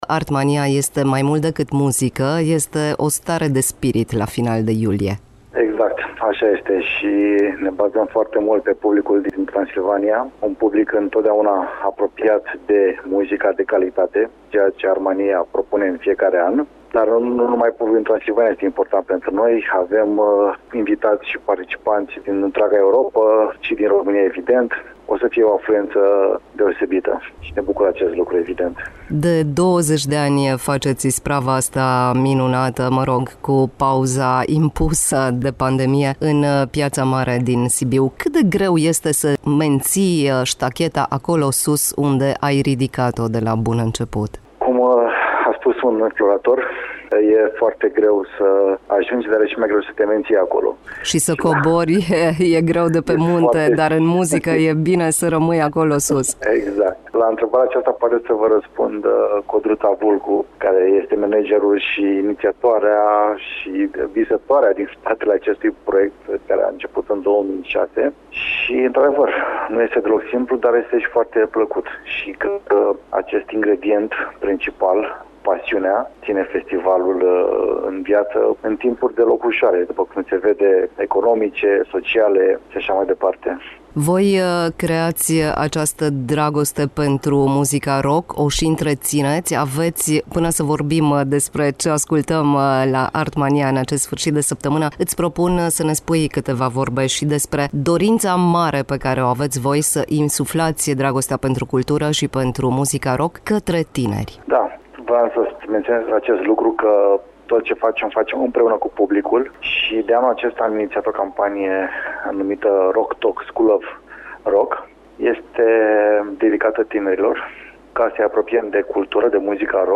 într-un dialog cu